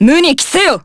Lucikiel_L-Vox_Skill1_jp.wav